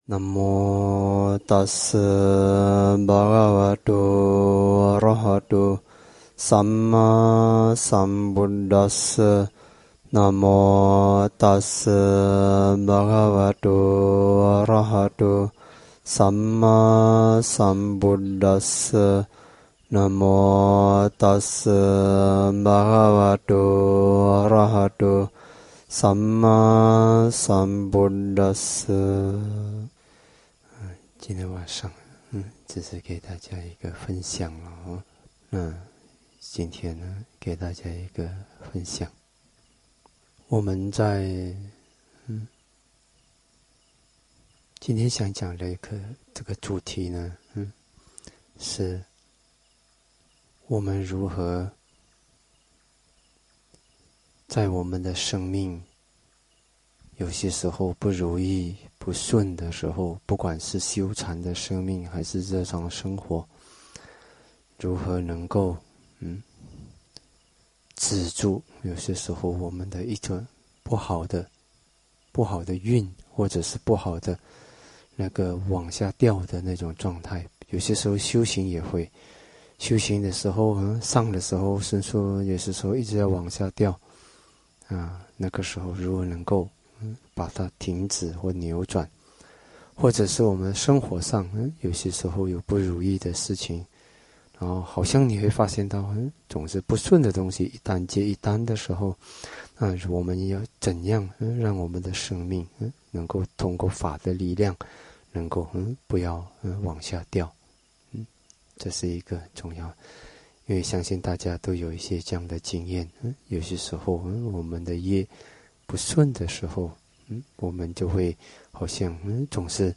171024 走出业的跌势 讲于传法生态林 ++《净觉之音》 电台弘法系列： 古晋Red FM 《净觉之音》 电台弘法系列 （新增：“ 什么是佛？